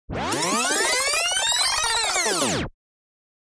جلوه های صوتی
دانلود صدای برگشت نوار به عقب 1 از ساعد نیوز با لینک مستقیم و کیفیت بالا